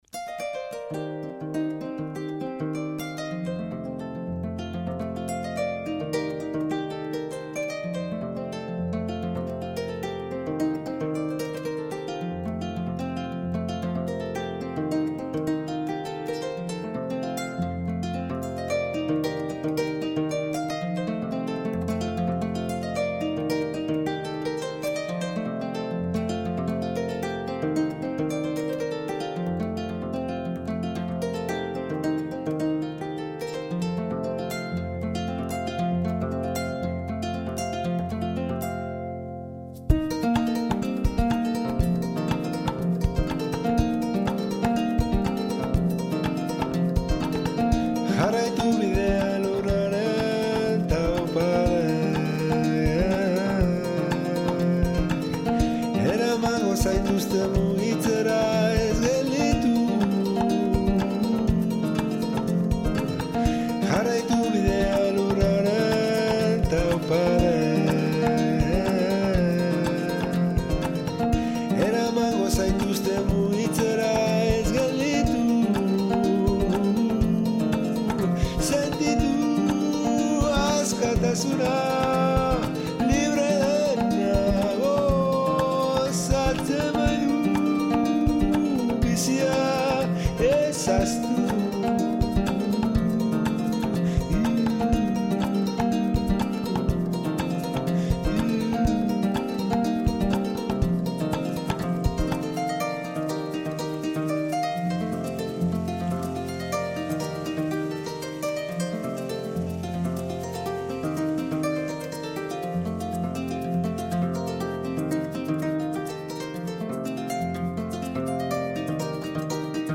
Diskoan argitaratu ditu, kora tresna lagun, egindako abestiak.